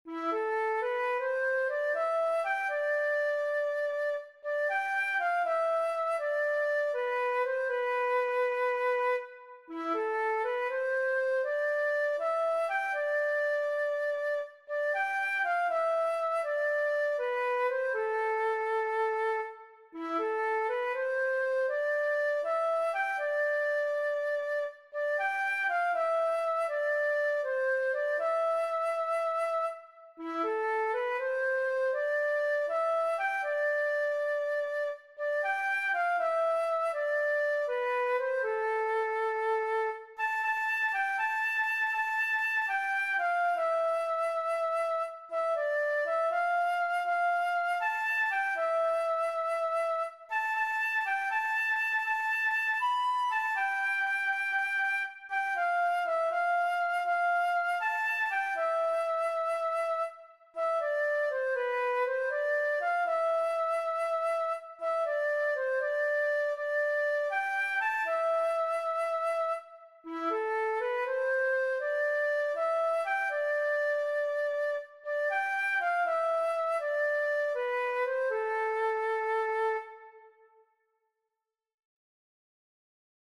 Melodías de bertsos - Ver ficha   Más información sobre esta sección
Estructura musical
A-B-C-D